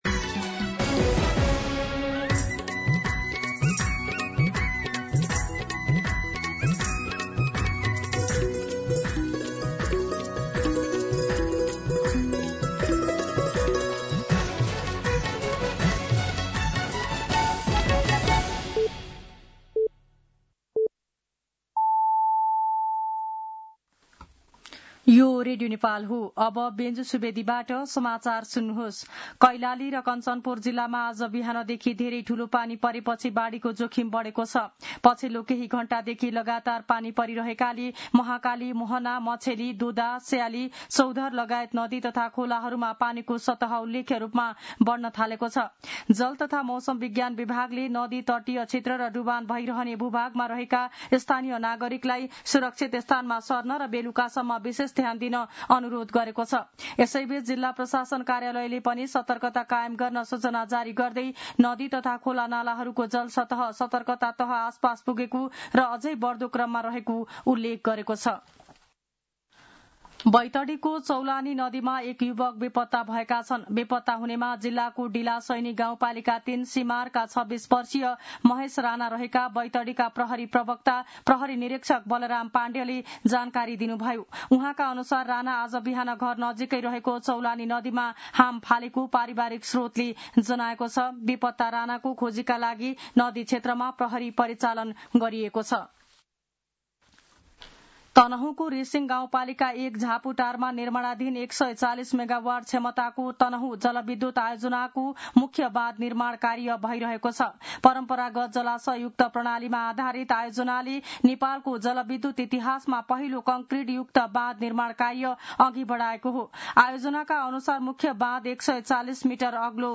मध्यान्ह १२ बजेको नेपाली समाचार : १६ असार , २०८२